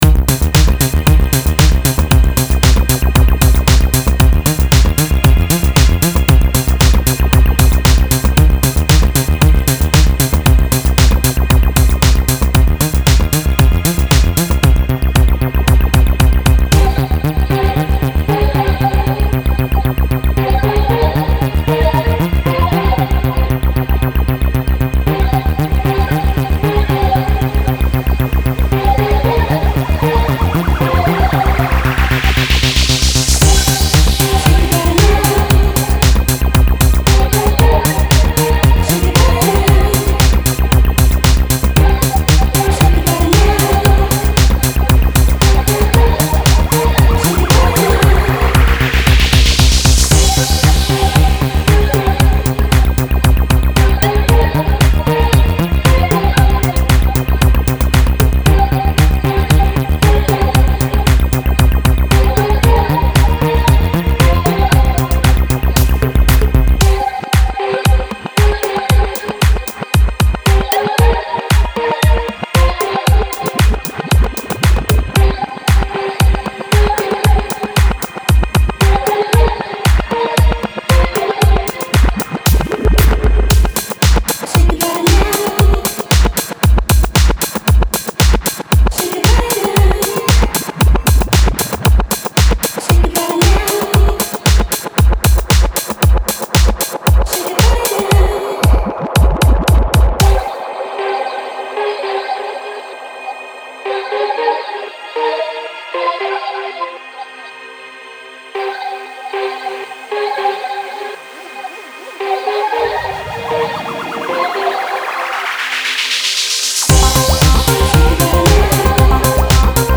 Style Style EDM/Electronic
Mood Mood Cool, Relaxed
Featured Featured Bass, Drums, Synth +1 more
BPM BPM 115